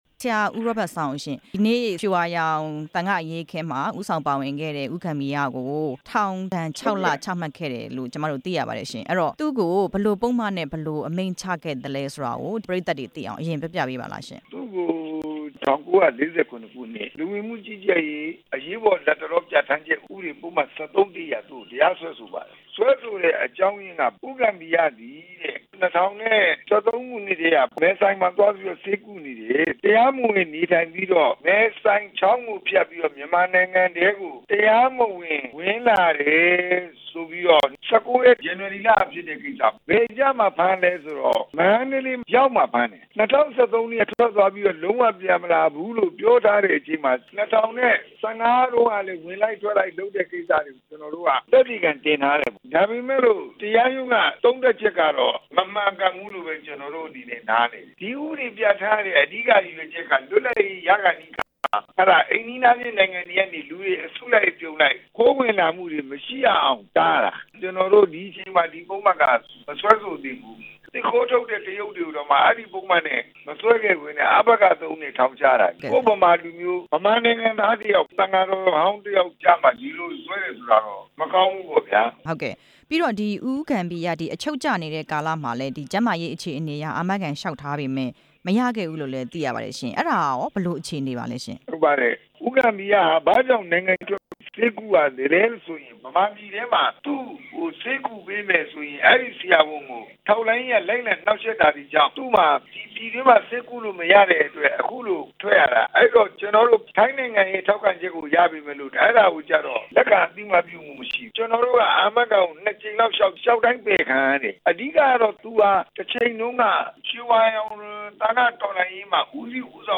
ဦးဂမ္ဘီရ ထောင်ချခံရမှု ရှေ့နေနဲ့ မေးမြန်းချက်